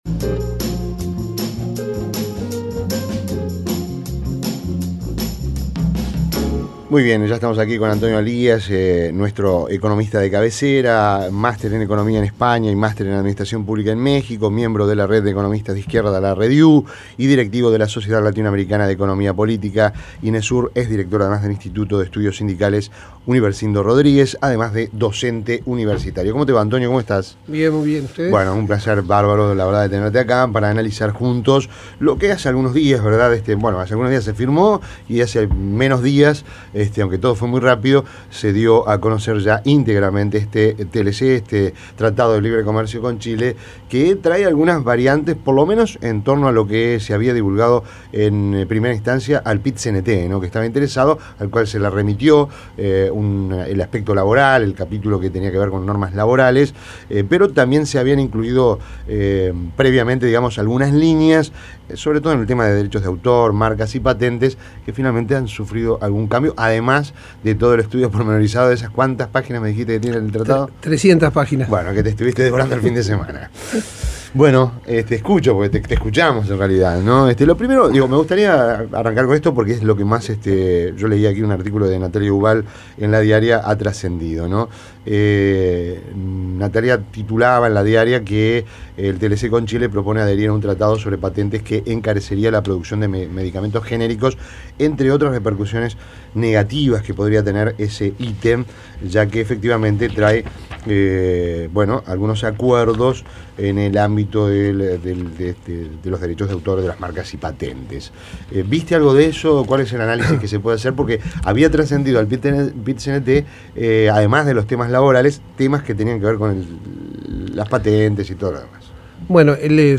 Entrevista en Rompkbzas